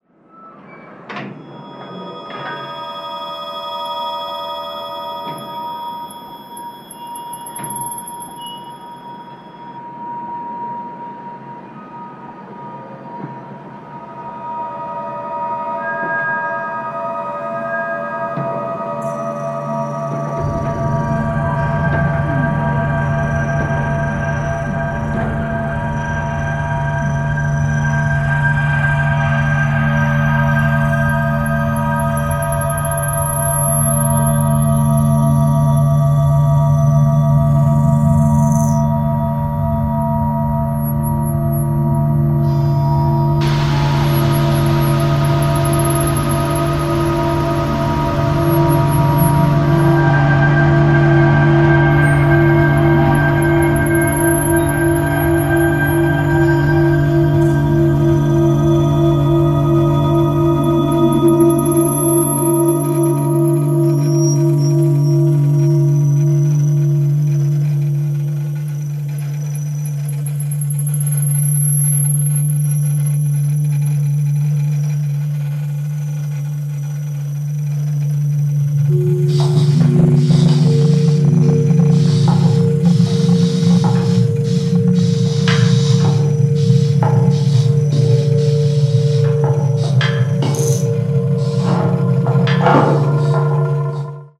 patiently unfolding electro-acoustic constructions